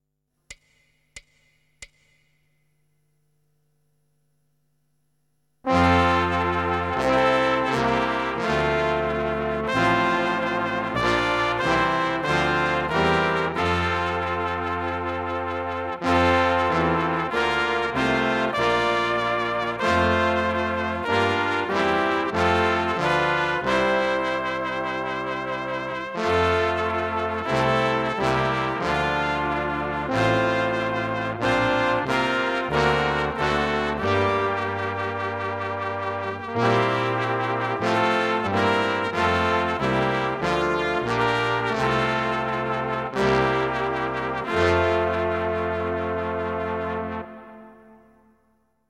Besetzung: Blasorchester
Choräle/Grablieder/Hymnen/Trauermärsche/Straßenmärsche.